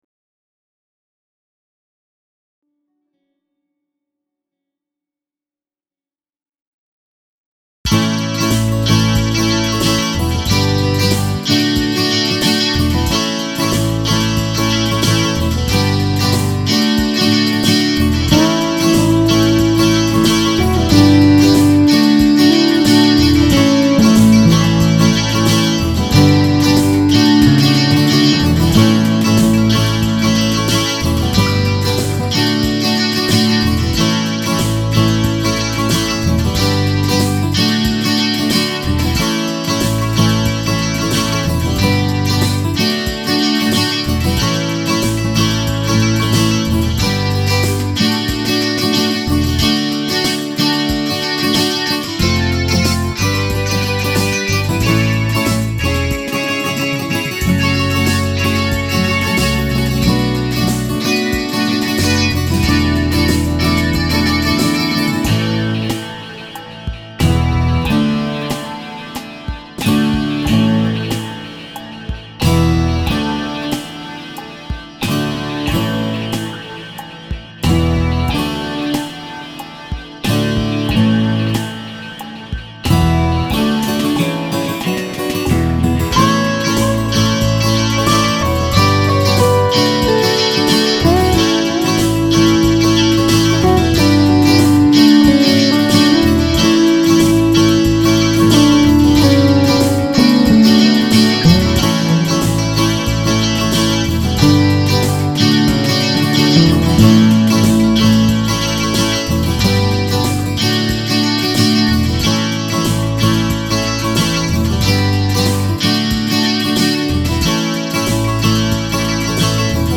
Hier ein paar Klangbeispiele, die mit virtuellen Instrumenten eingespielt wurden.